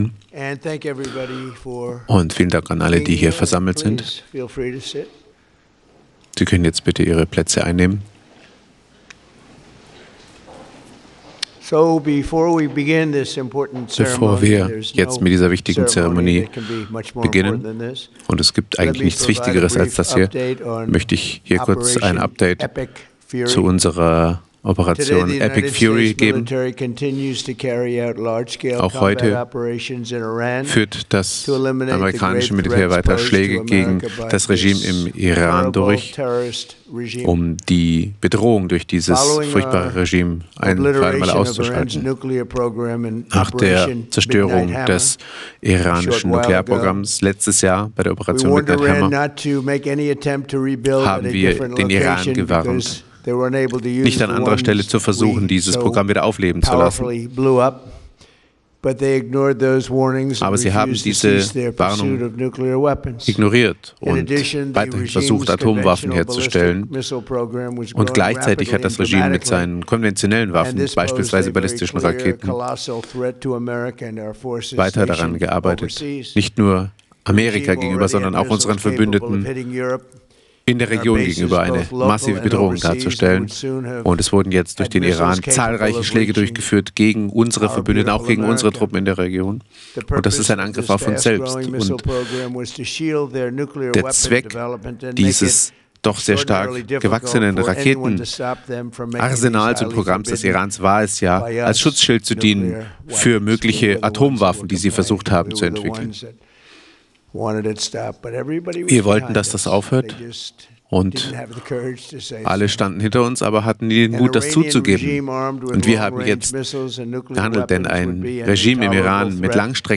Bei seiner Rede vor der Verleihung